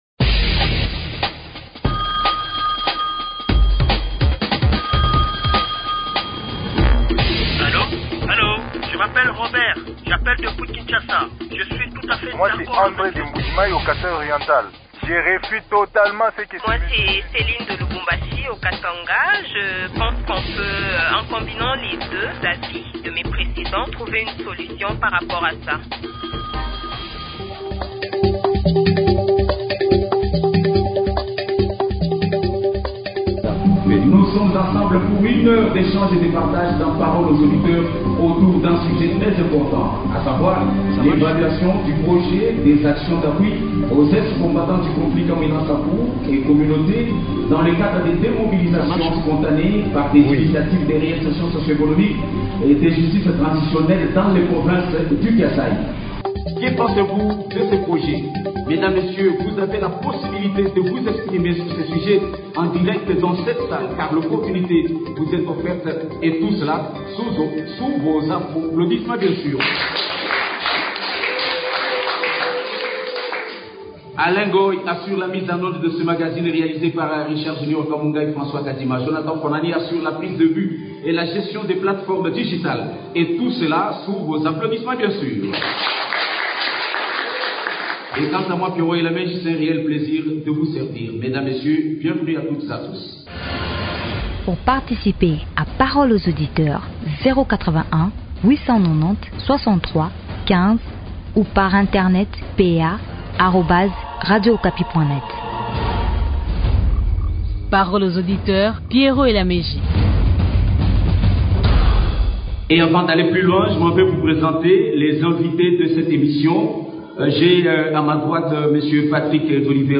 Edition spéciale à Kananga au Kasaï Central